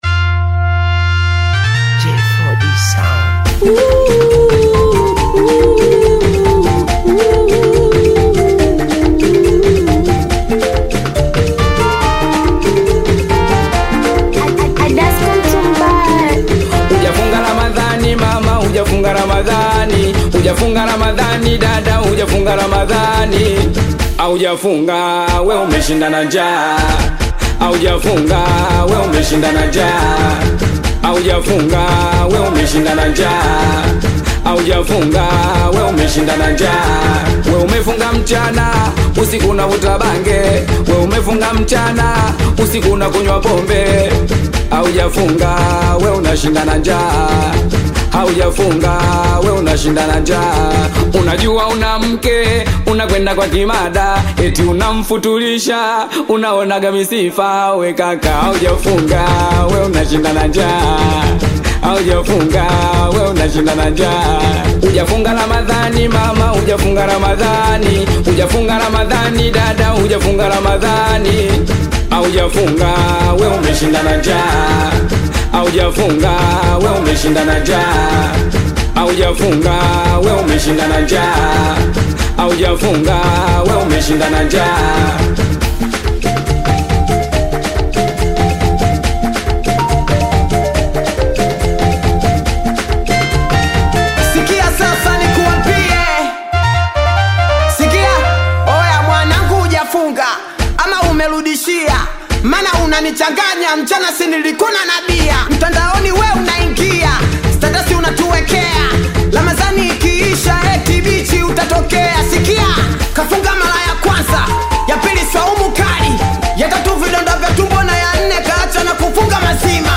Tanzanian Bongo Flava Singeli